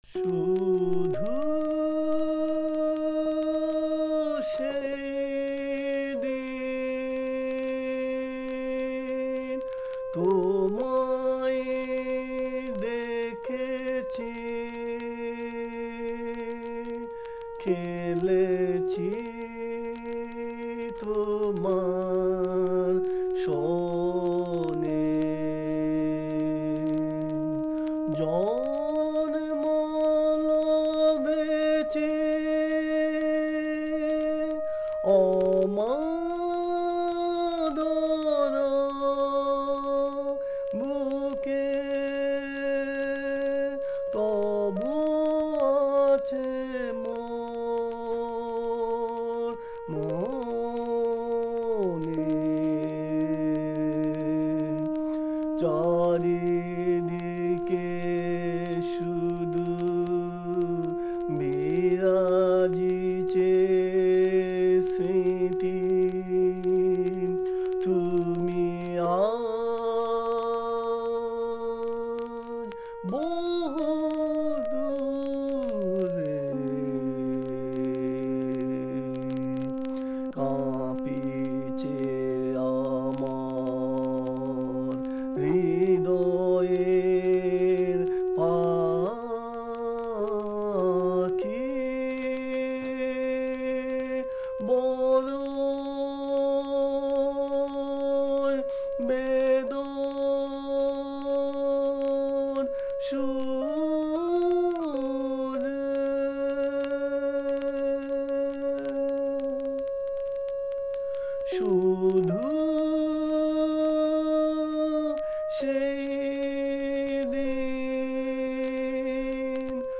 accompanied by the harmonium.